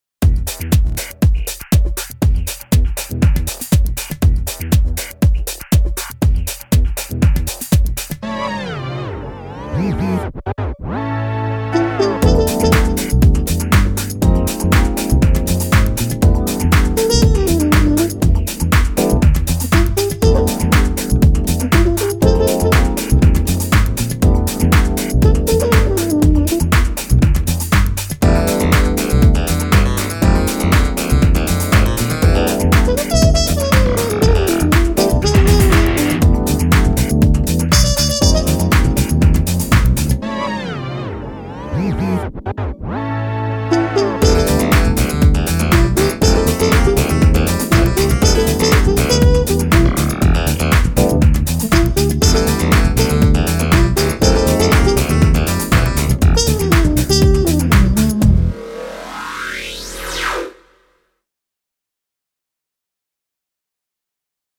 keyboards
trumpet
saxophone
violin
guitars
bass
drums